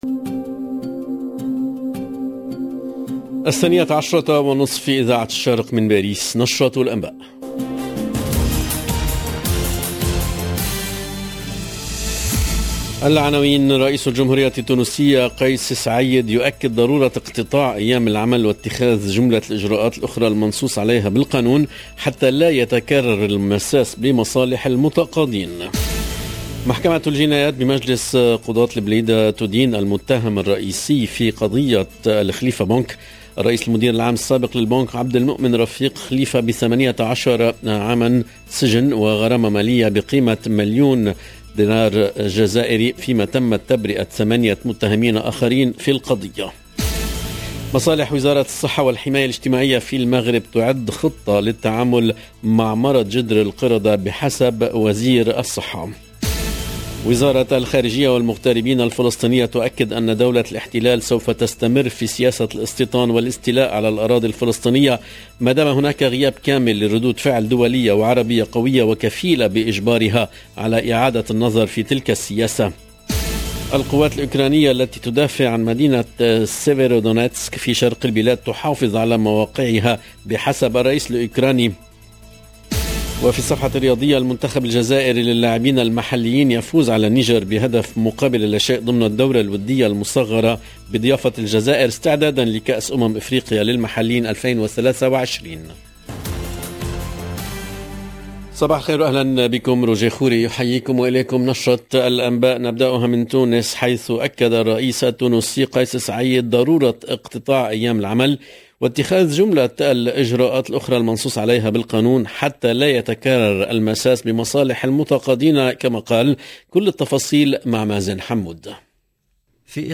LB JOURNAL EN LANGUE ARABE